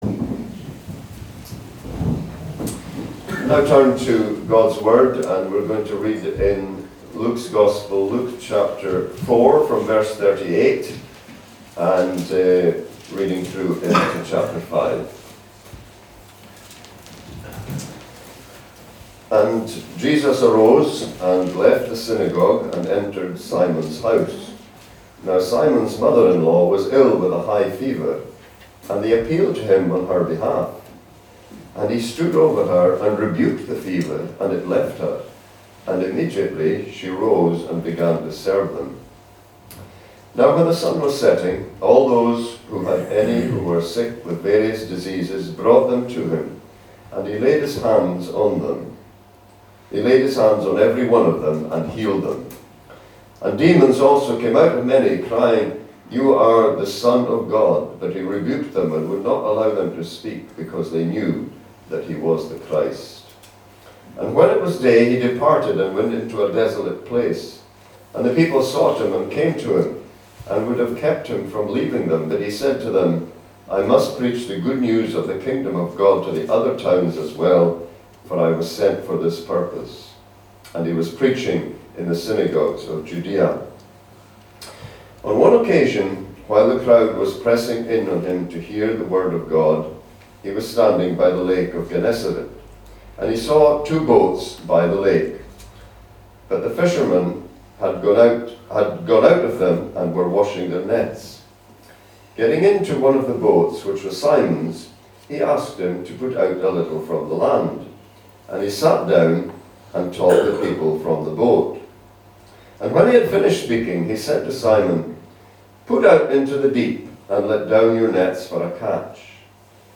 A link to the video recording of the 6:00pm service, and an audio recording of the sermon.